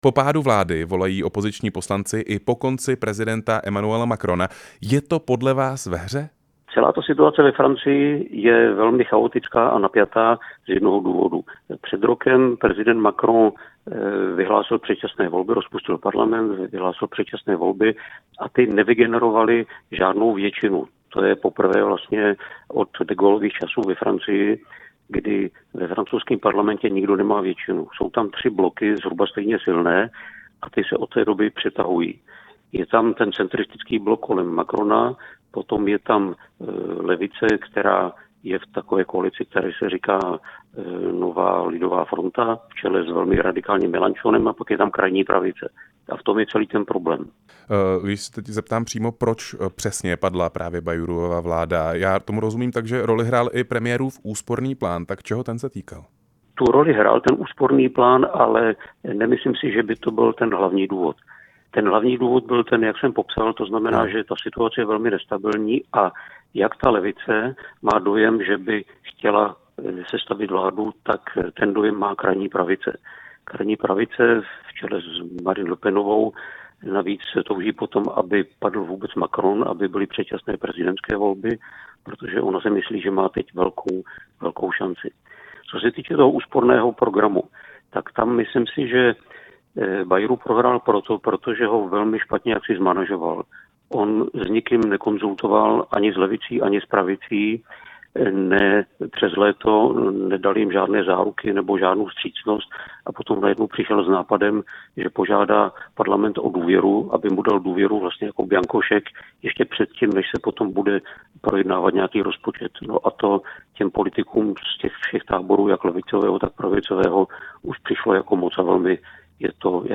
Rozhovor s bývalým velvyslancem ve Francii Petrem Janyškou